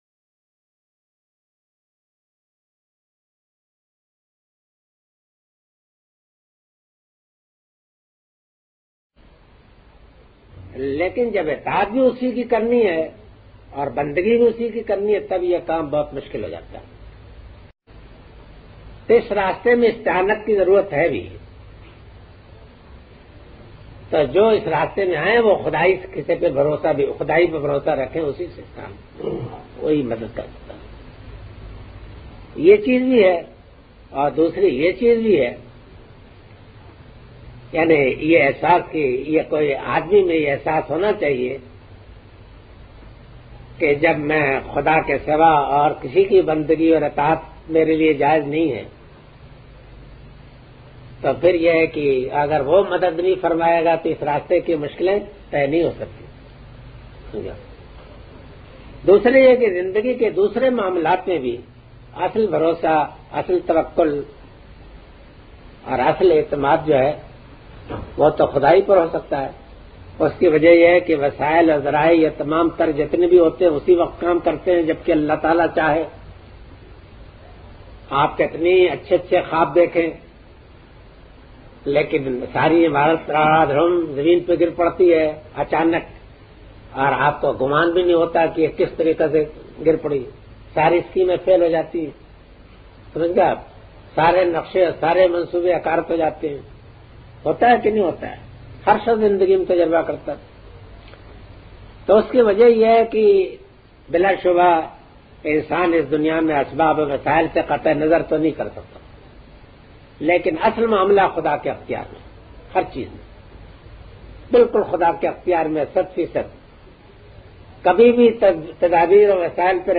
Imam Amin Ahsan Islahi's Dars-e-Qur'an.